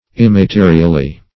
Meaning of immaterially. immaterially synonyms, pronunciation, spelling and more from Free Dictionary.
Search Result for " immaterially" : The Collaborative International Dictionary of English v.0.48: Immaterially \Im`ma*te"ri*al*ly\, adv. 1.